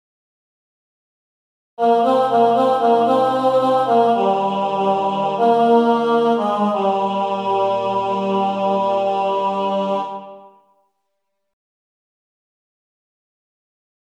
Key written in: E♭ Major
Type: Other male